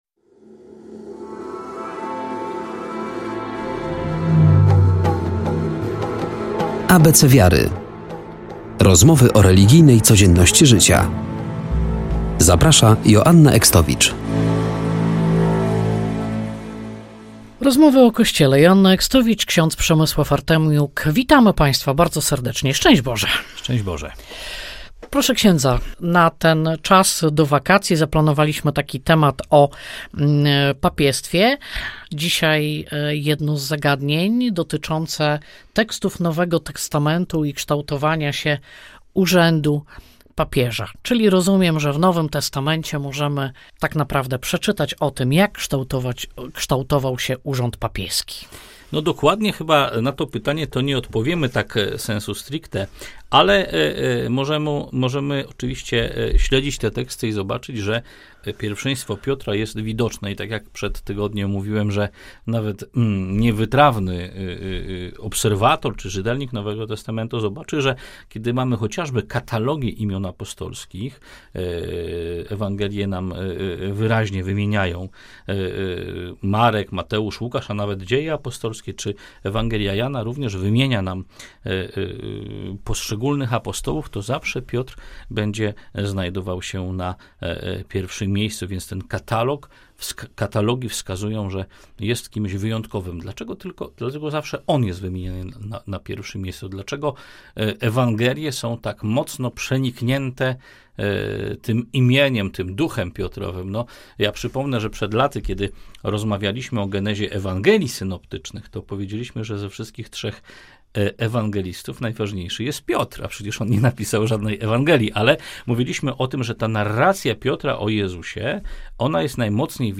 Kolejna audycja w której przyglądamy się urzędowi papieża.